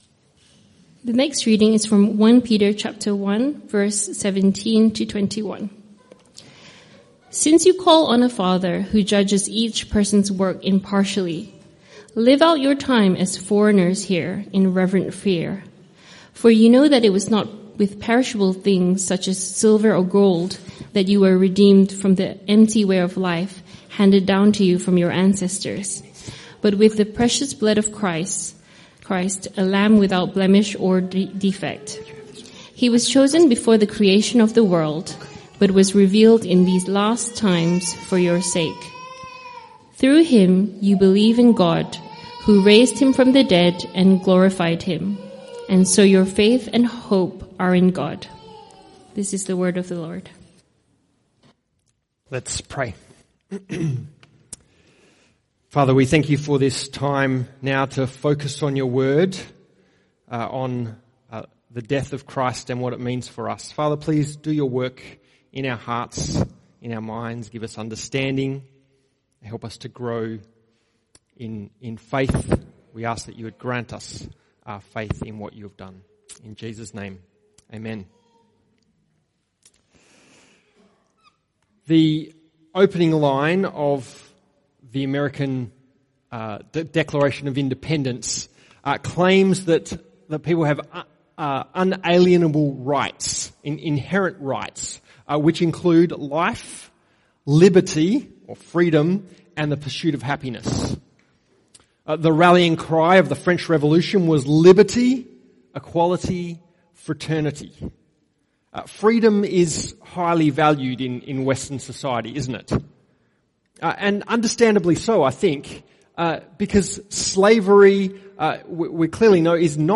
CBC Service: 18 April 2025 Series
Type: Sermons